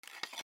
古びた木片 小さな物音
『シャラ』